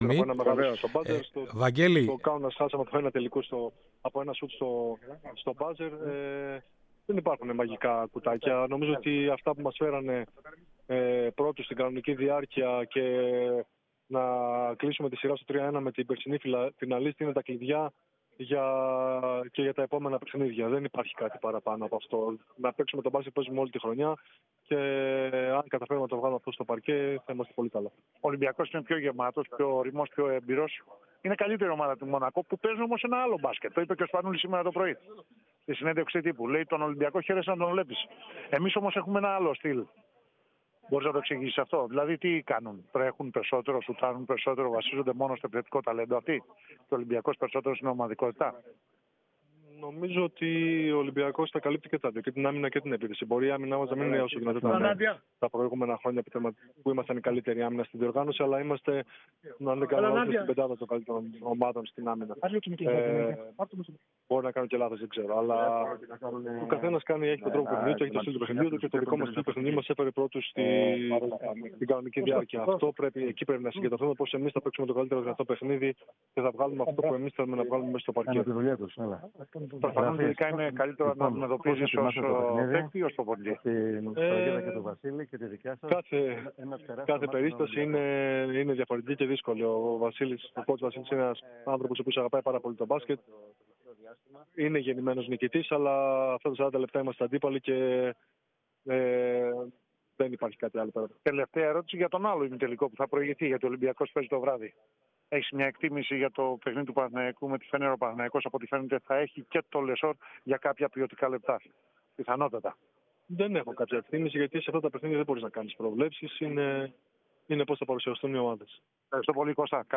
Ακούστε τις δηλώσεις του Παπανικολάου: